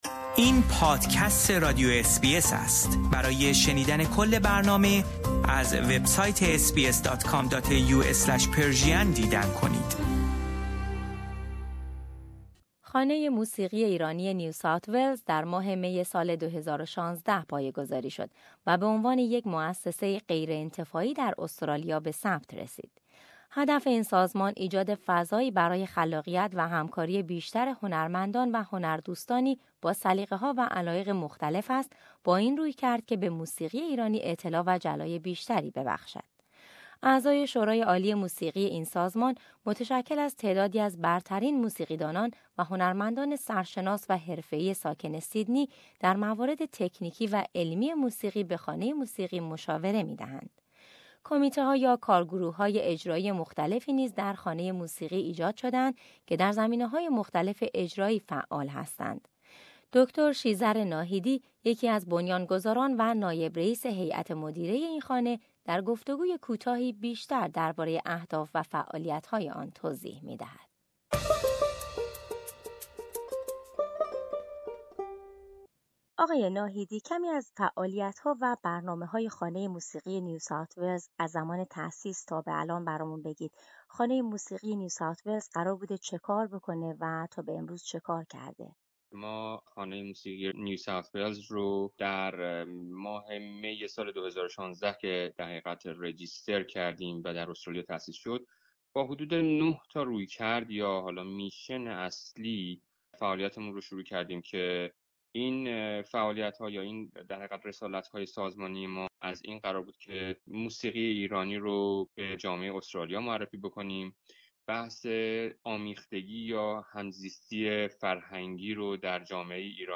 گفت و گوی کوتاهی